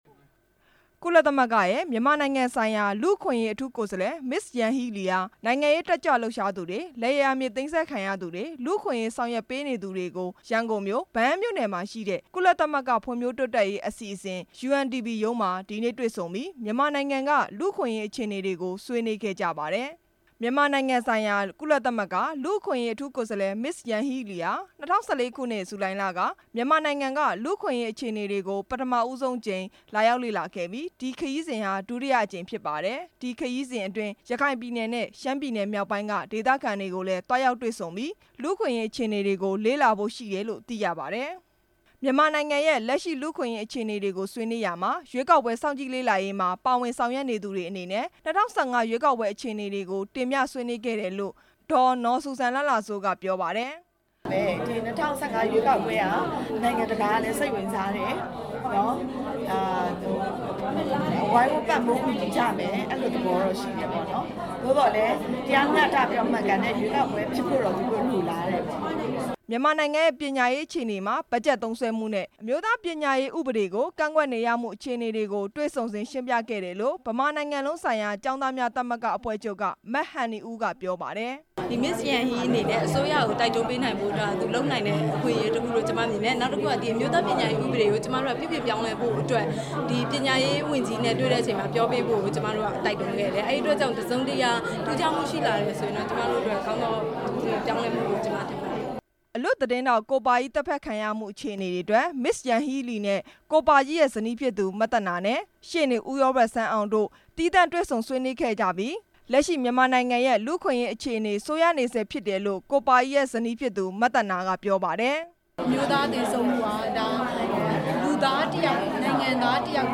သတင်းစာရှင်းလင်းပွဲအကြောင်း တင်ပြချက်